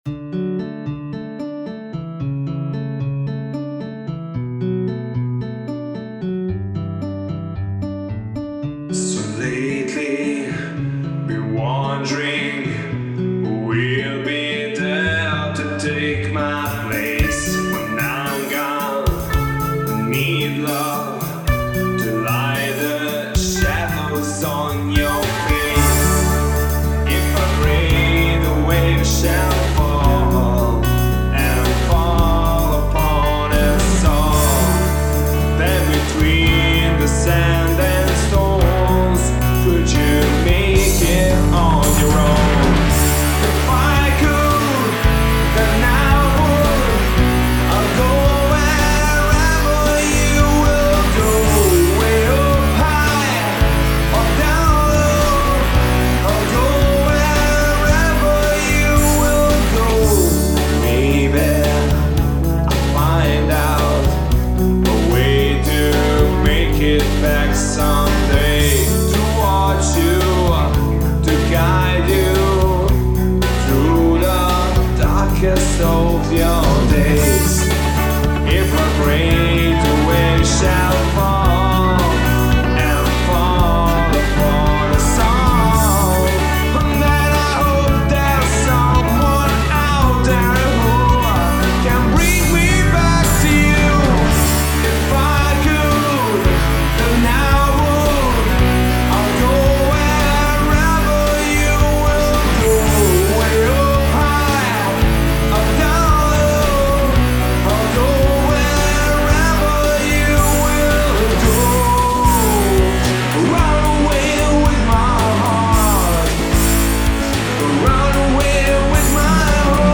in versione (molto!) artigianale